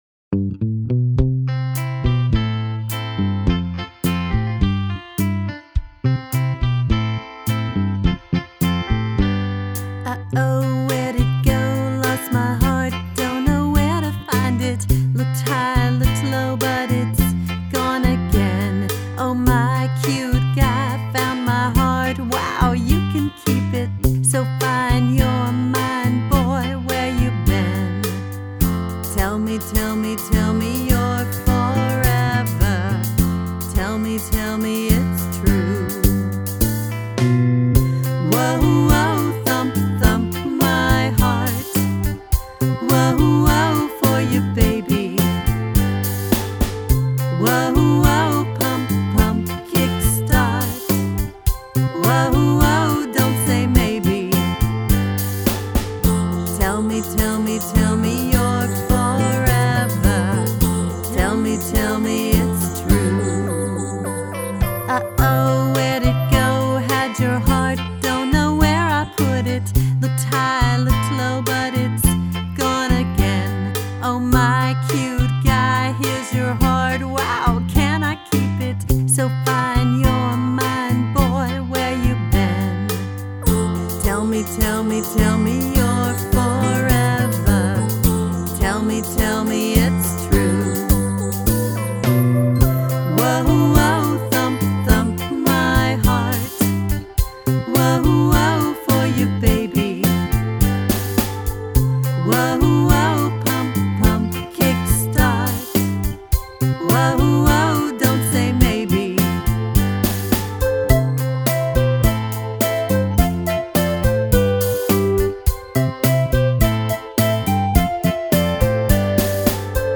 Below you can listen to a song we wrote together a few years ago. I sang all the parts, one at a time, and I remember him distinctly saying, “Try to sound like a dumb blonde.”